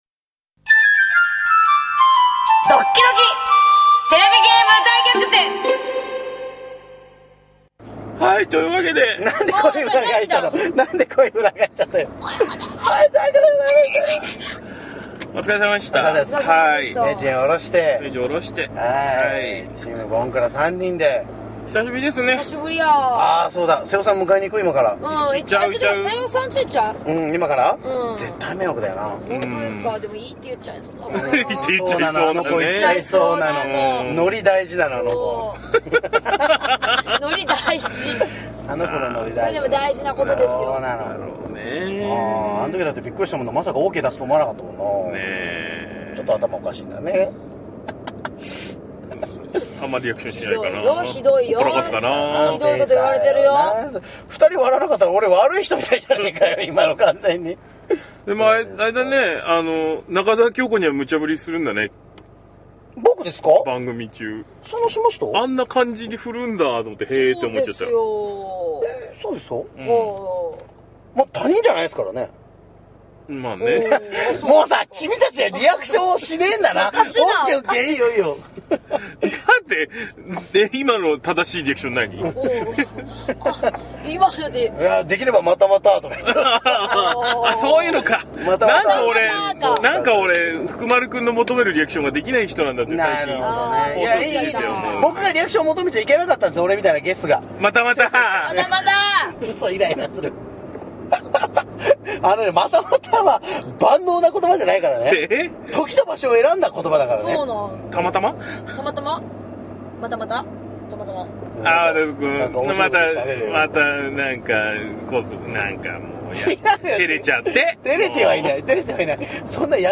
TVゲーム大逆転」放送後の反省会ポッドキャスト Part2 高橋名人を降ろしたあとに収録したポッドキャスト。 まぁー、聞いてても話があっちこっちに行ってとりとめないこと。